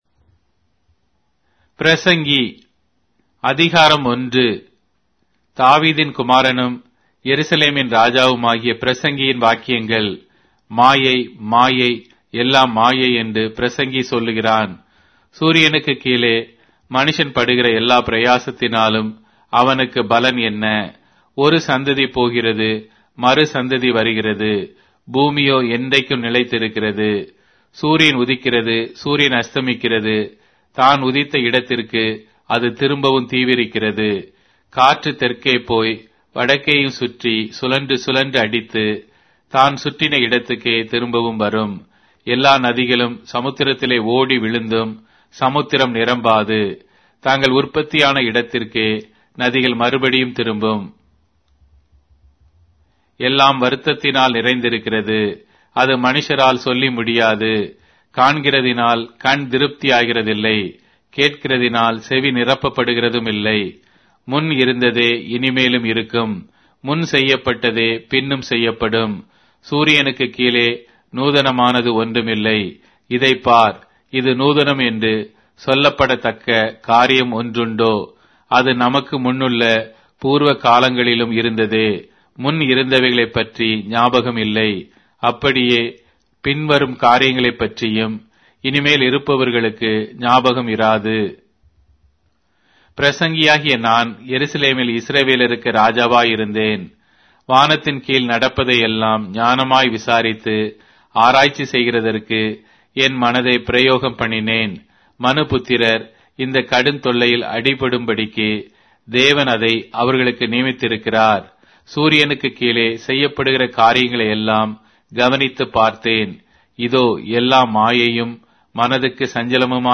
Tamil Audio Bible - Ecclesiastes 7 in Ervte bible version